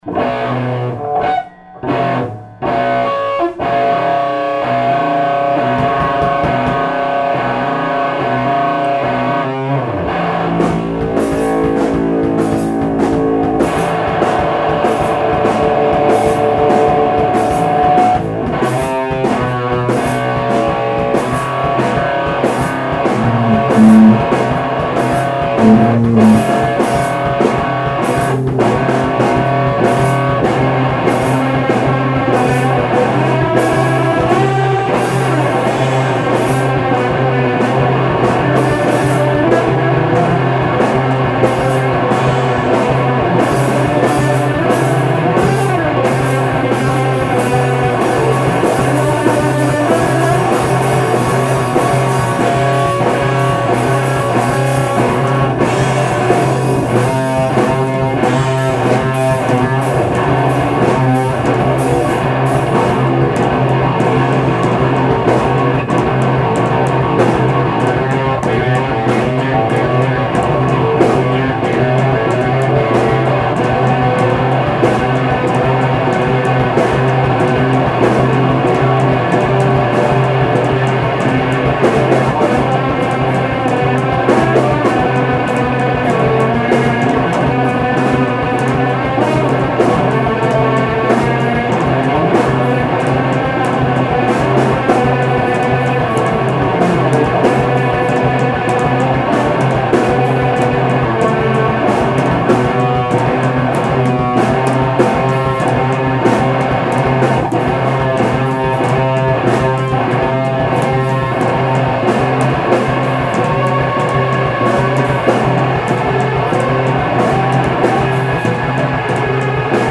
8.27.20 Live Stream — Moon Pussy
More riffing